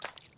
Footsteps
puddle5.ogg